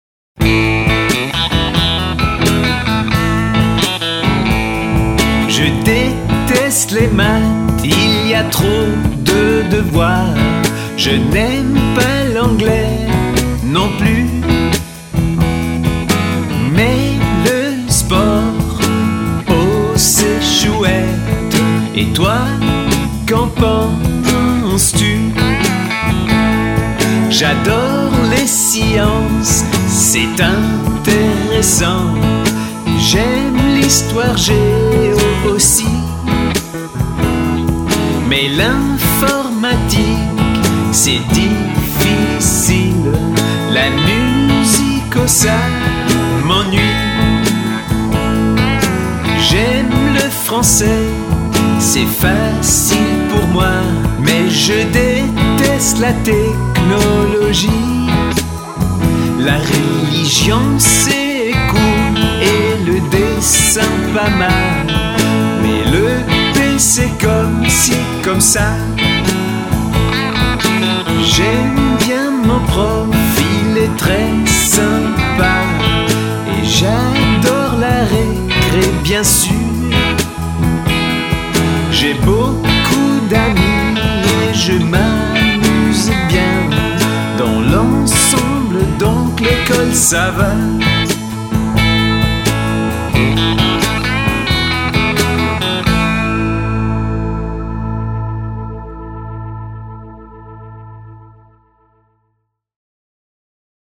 Listen to the story 'Luc et Sophie font les devoirs' performed by native French speakers